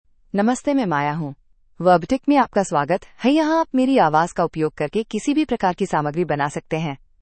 Maya — Female Hindi (India) AI Voice | TTS, Voice Cloning & Video | Verbatik AI
Maya is a female AI voice for Hindi (India).
Voice sample
Listen to Maya's female Hindi voice.
Female
Maya delivers clear pronunciation with authentic India Hindi intonation, making your content sound professionally produced.